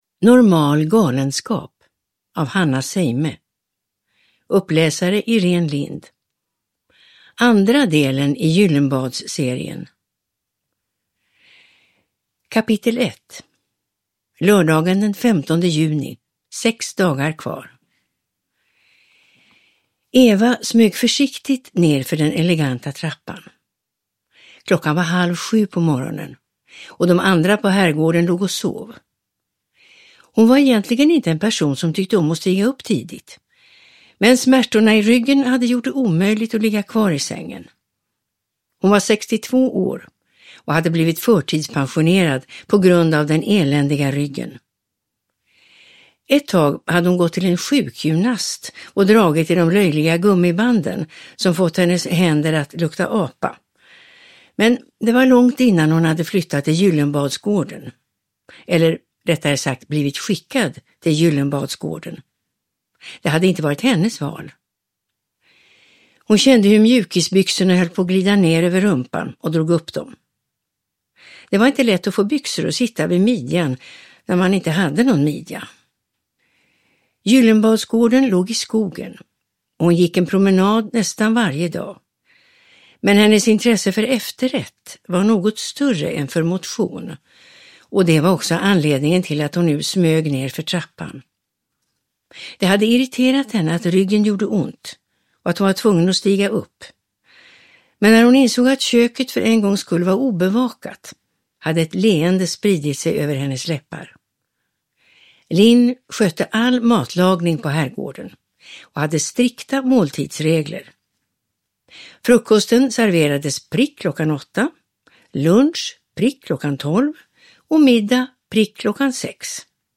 Normal galenskap – Ljudbok
Uppläsare: Irene Lindh